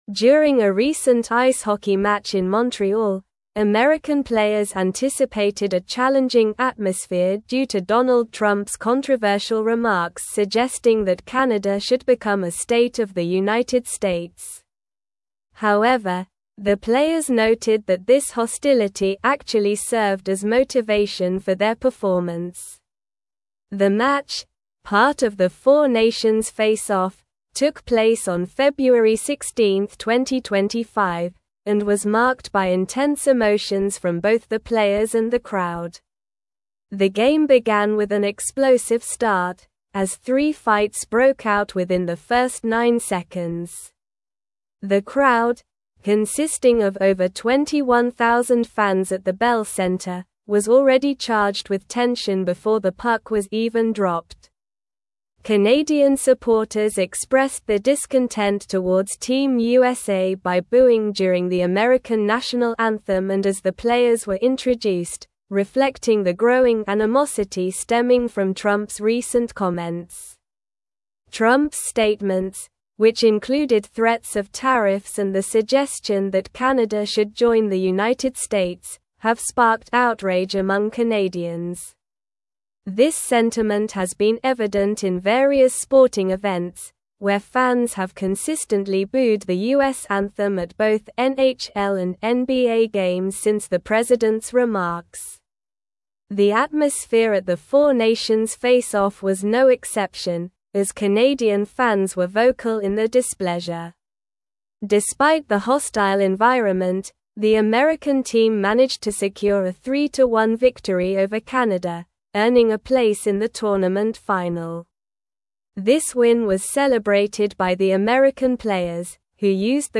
Slow
English-Newsroom-Advanced-SLOW-Reading-Intense-Rivalry-Ignites-During-4-Nations-Face-Off.mp3